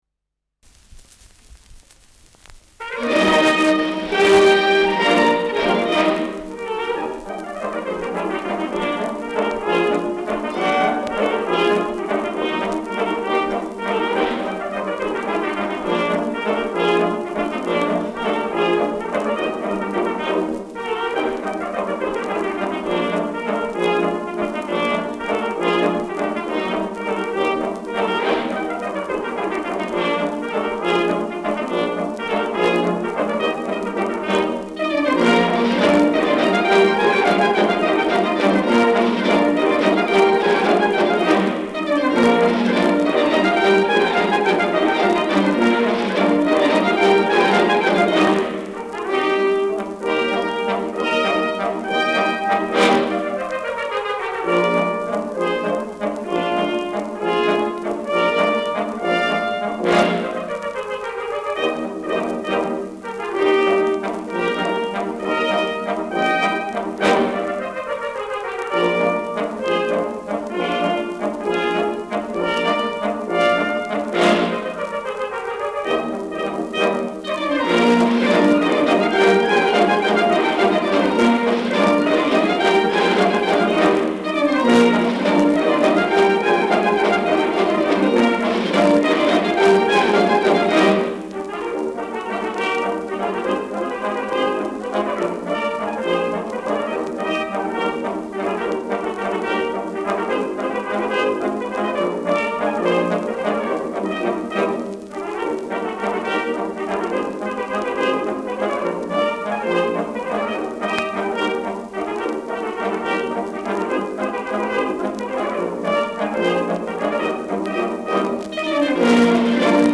Schellackplatte
leichtes Rauschen : leichtes Knistern
Fränkische Bauernkapelle (Interpretation)
Mit Juchzern und Ausruf am Ende (“Hau zua!“).